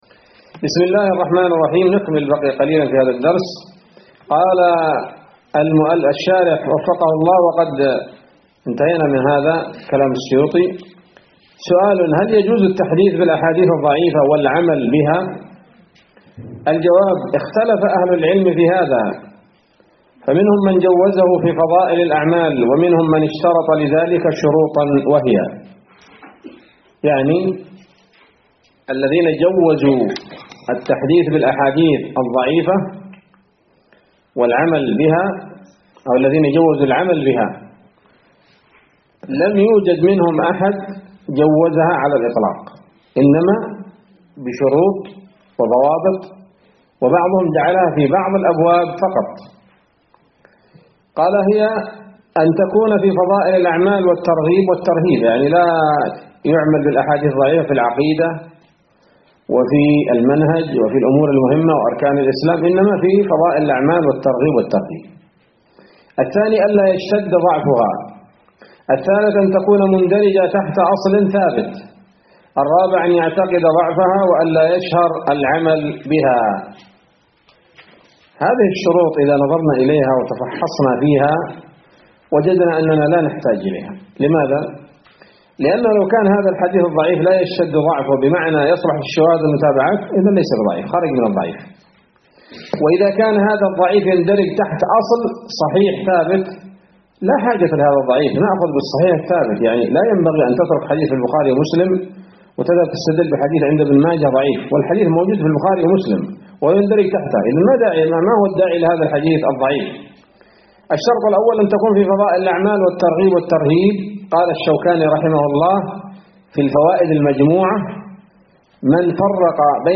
الدرس التاسع من الفتوحات القيومية في شرح البيقونية [1444هـ]